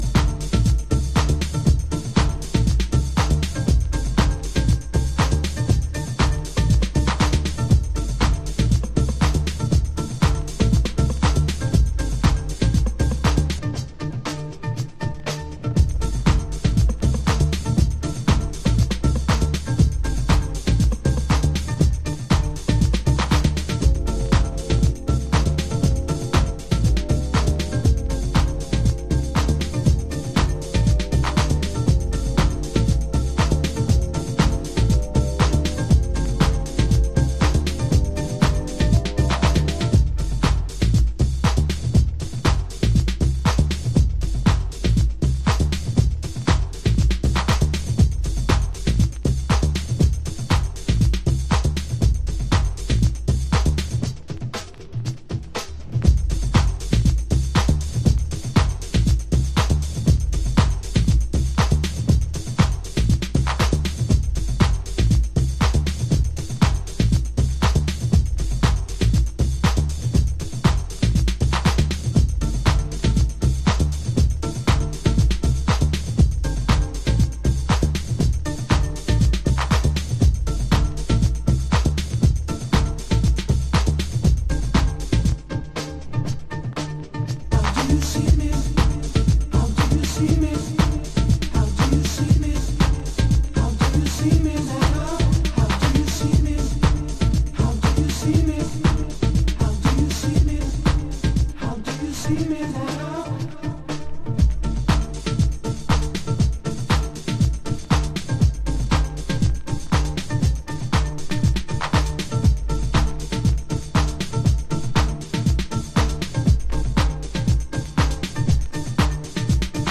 心に滲みるディープハウスオブソウル。